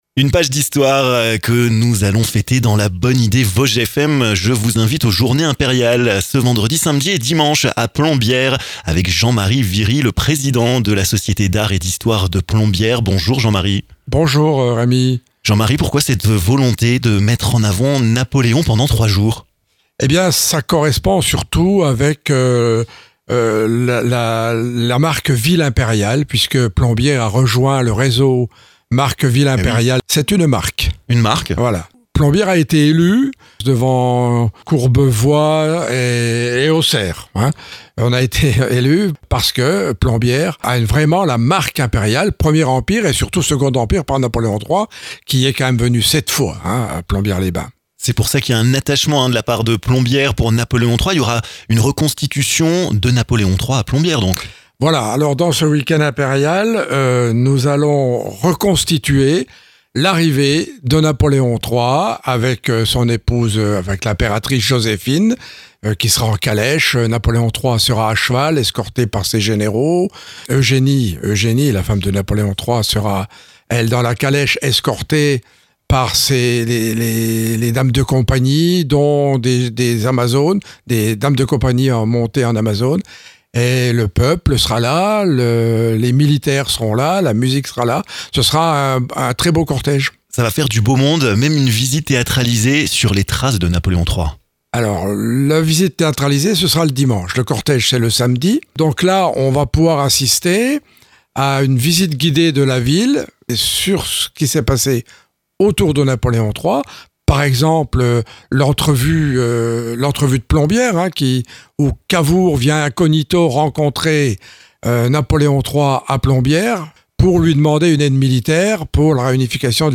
a accepté de venir dans nos studios pour vous présenter les journées impériales. Trois jours de fête en l'honneur de Napoléon III avec un défilé, une exposition, une reconstitution, un dîner, et beaucoup d'autres animations!